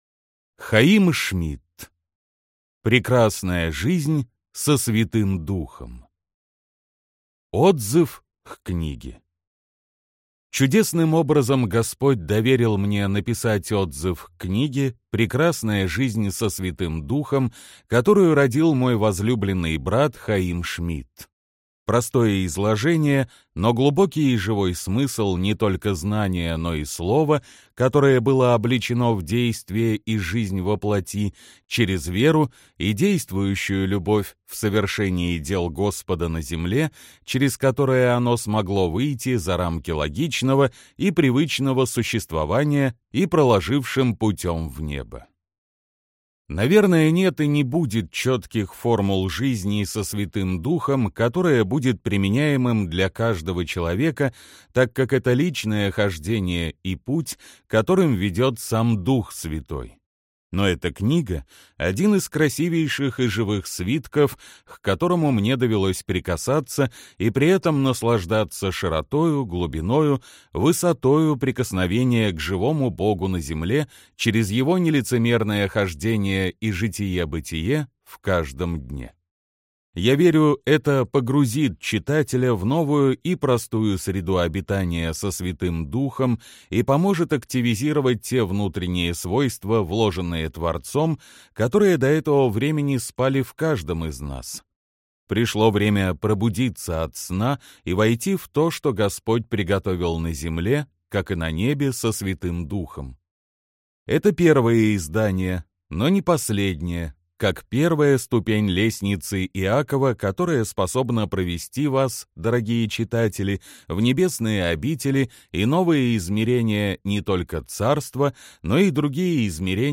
Аудиокнига Прекрасная жизнь со Святым Духом | Библиотека аудиокниг